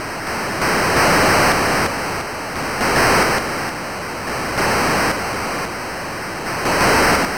ANNCheering3.wav